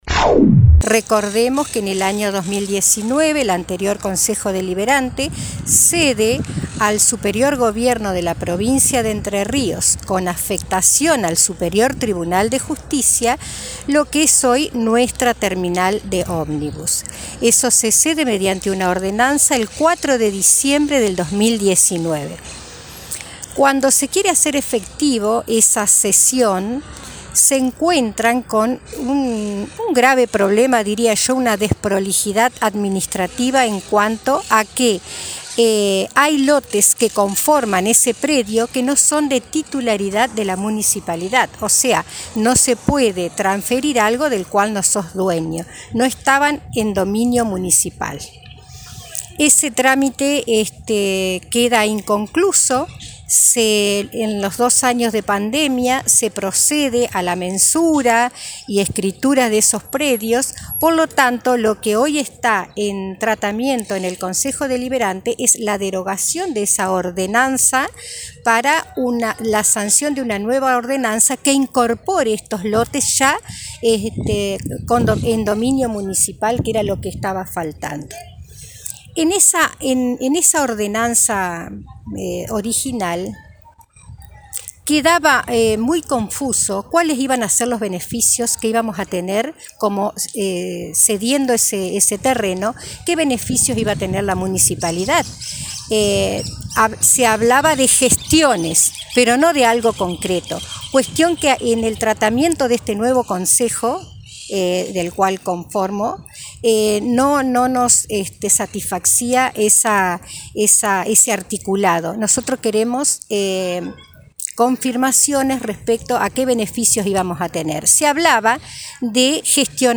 En diálogo con este medio, la viceintendenta de Victoria, Ana Schuth, dialogó respecto a este tema y comentó que la ordenanza por la cual se tenía previsto ceder el espacio para la ubicación de oficinas del Poder Judicial, cedía lotes que no pertenecían, en los papeles, a la Municipalidad de Victoria.